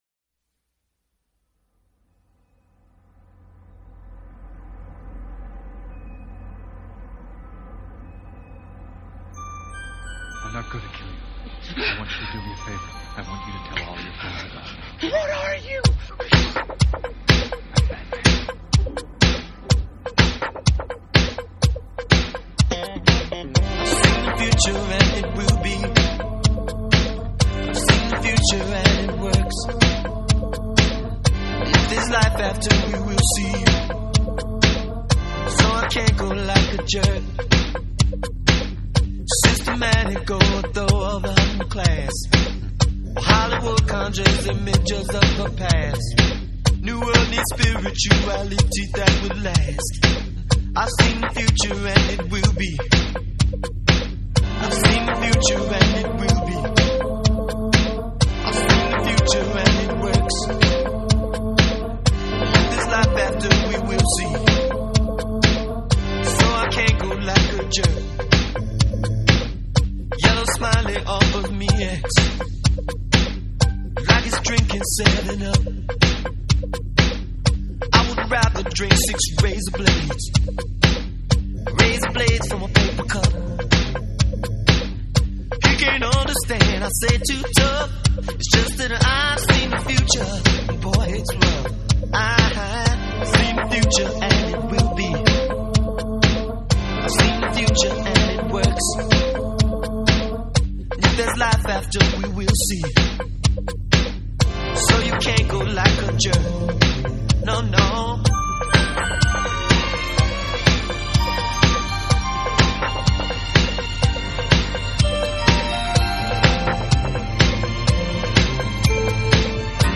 Pop, Funk